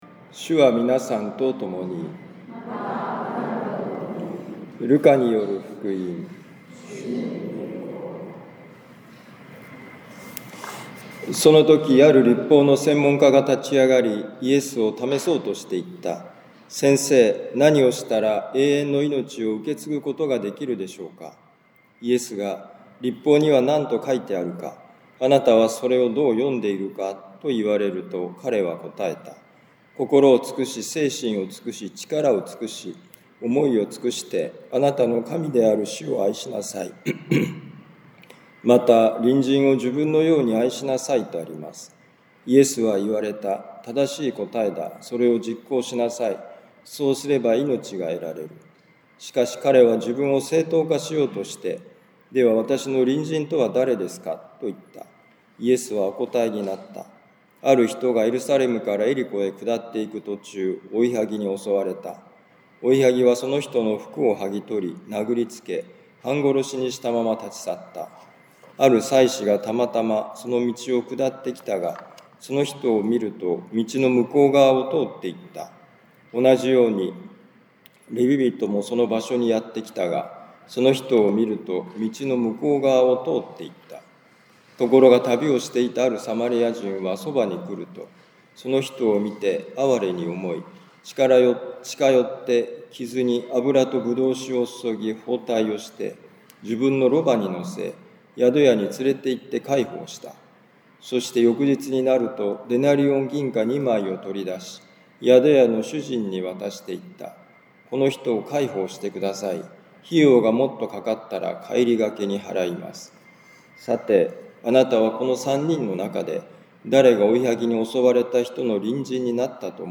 ルカ福音書10章25-37節「隣人となって助け合う」2025年７月13日年間第15主日カトリック長府教会